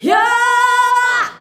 YOOOAAH B.wav